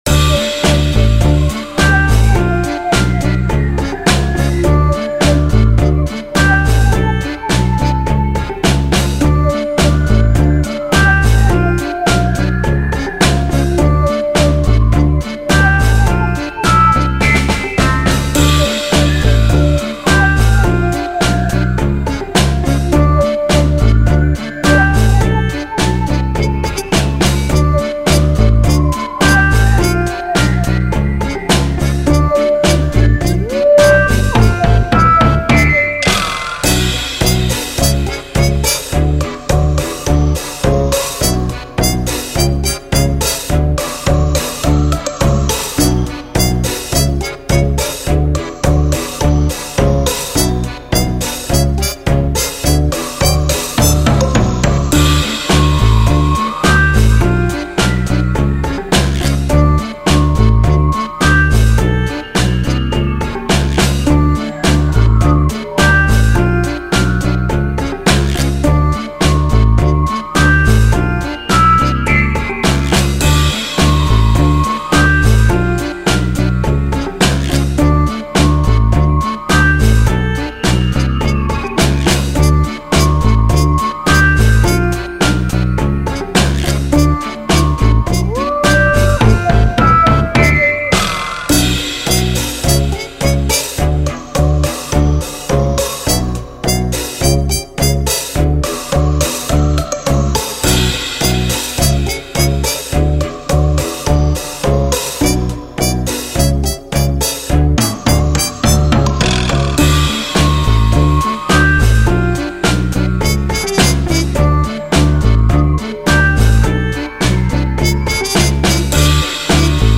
フリーBGM その他
軽快なテンポとユニークな音使いがワクワク感と緊張感の入り混じった“こわかわいい”世界観を演出します。
木管系の音色がメロディを彩りオバケや妖怪、探検や発見、ちょっと怖いけど楽しい空気をやさしく包み込みます。
• テンポは中速（約90BPM）で、リズムは比較的シンプル
• 木管楽器の音源を中心に構成し、耳に優しいけれど不穏な響きを演出
• リバーブとディレイで空間的な広がりと神秘感をプラス
テンポの揺れやダイナミクスに少しだけ“人間っぽさ”を加えています。